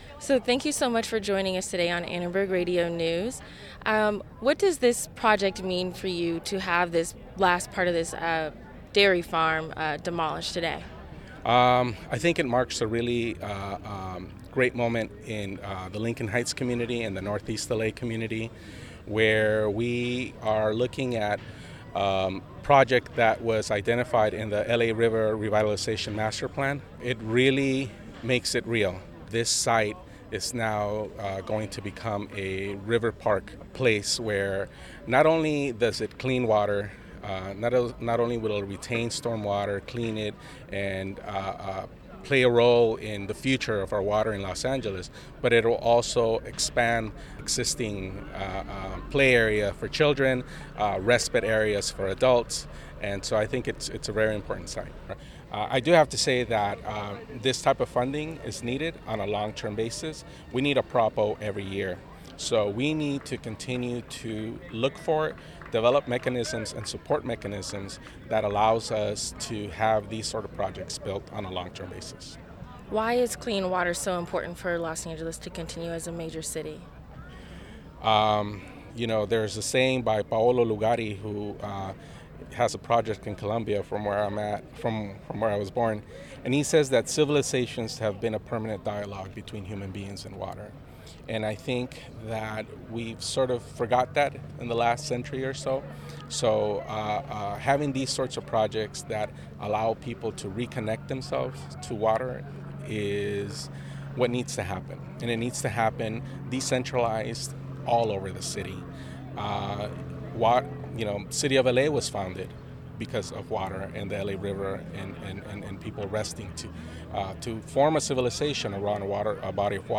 A demolition ceremony to clear land for a park on the Los Angeles River in Lincoln Heights presided over by Mayor Antonio Villaraigosa and Councilman Ed Reyes. The city purchased the site two years ago with Proposition O funds, a voter approved clean water bond measure.